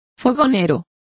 Complete with pronunciation of the translation of stoker.